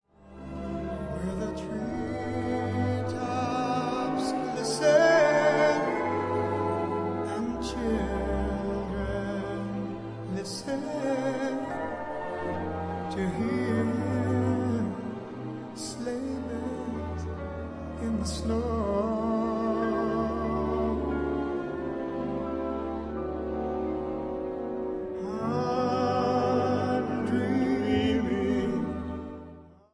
key: E-major/F-major